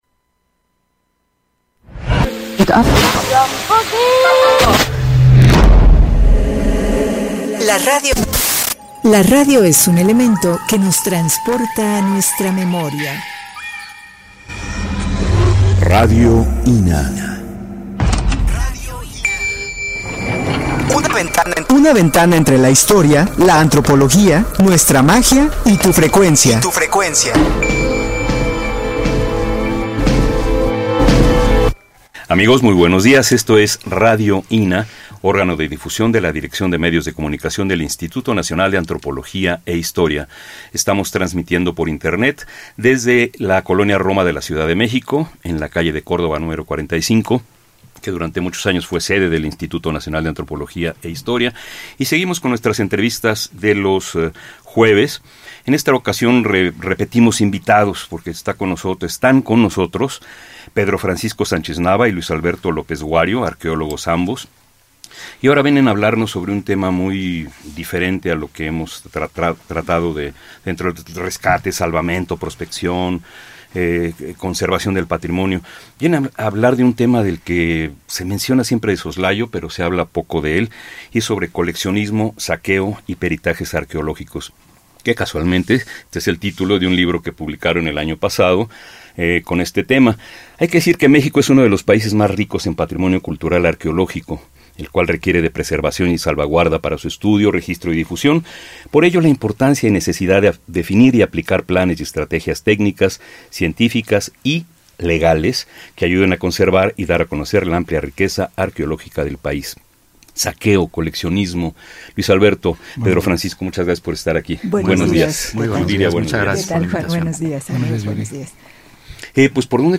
entrevista_26-Service File.mp3